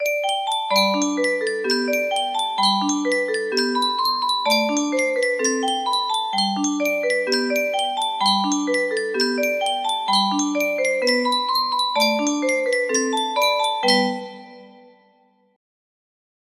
Clone of Sankyo Music Box - How Dry I Am FR music box melody